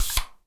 spray_bottle_04.wav